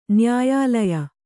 ♪ nyāyālaya